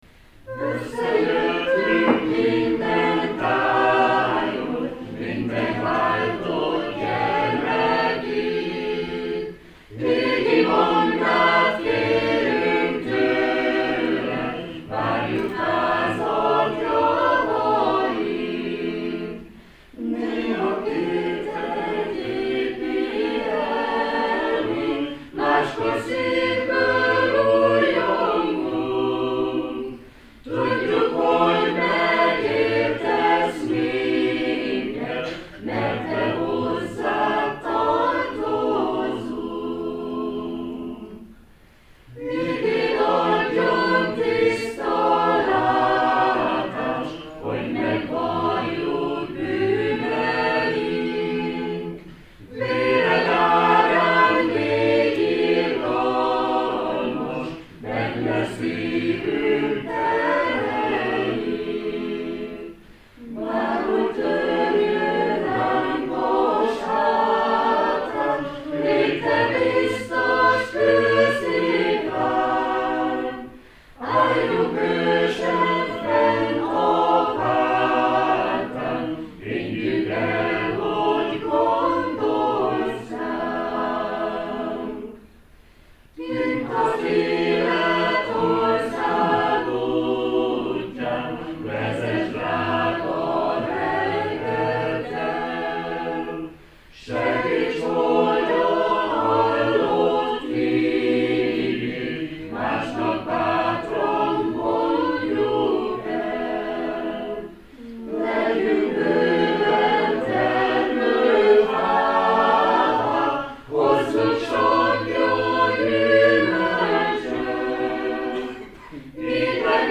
A baptista kórus énekel.